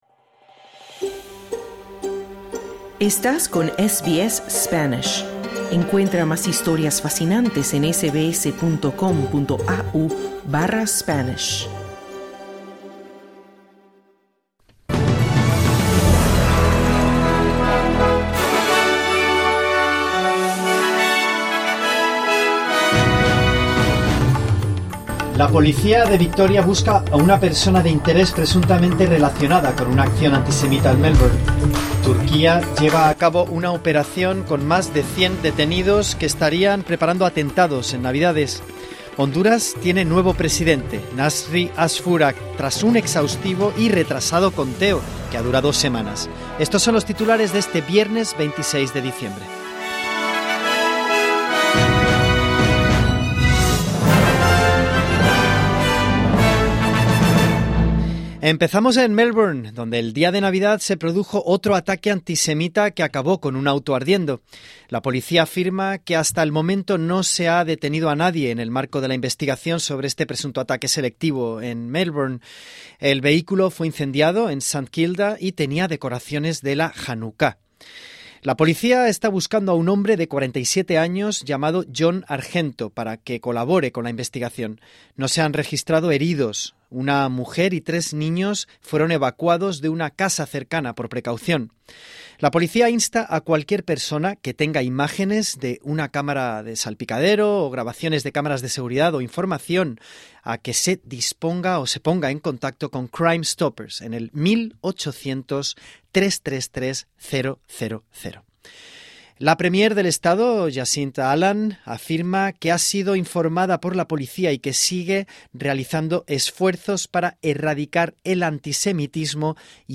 En el boletín de noticias del viernes 26 de diciembre, la policía de Victoria busca una persona de interés presuntamente relacionada con una acción antisemita, Turquía lleva a cabo una operación con más de 100 detenidos que estarían preparando atentados en Navidades, Honduras tiene nuevo presidente, Nasry Asfura.